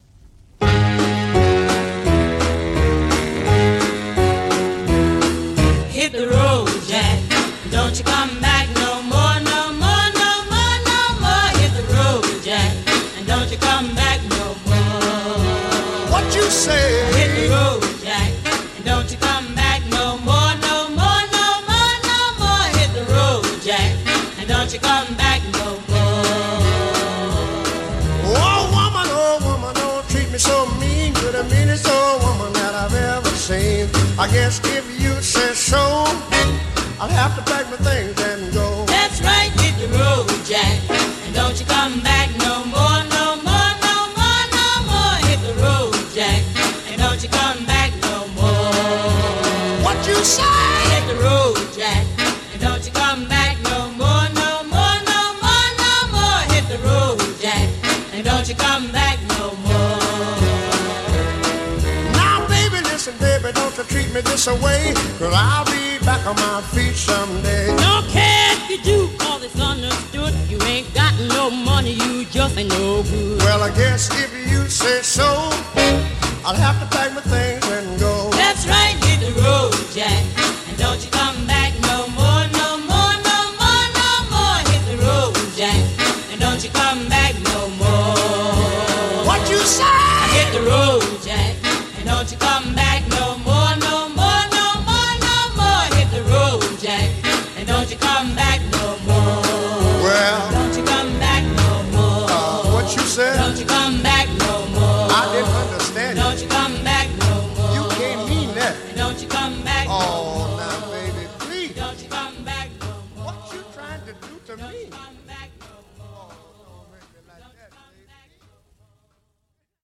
Жанр: R&B